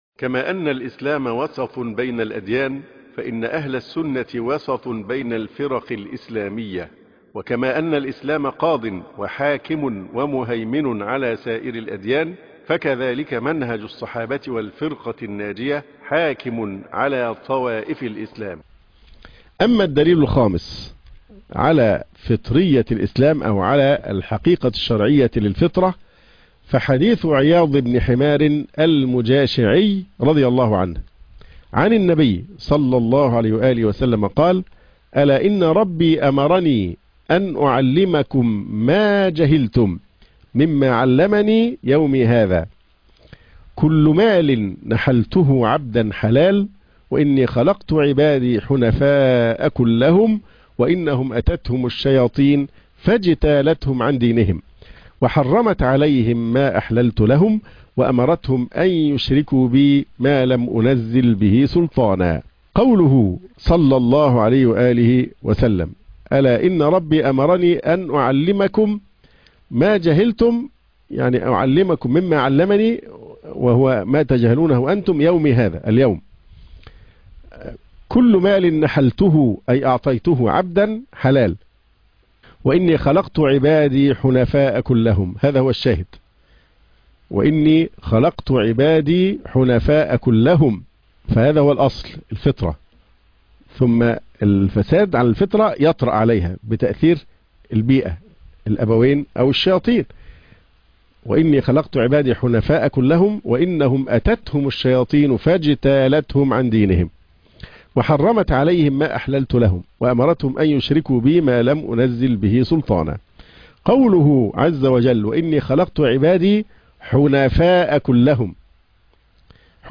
شرح حديث عياض المجاشعي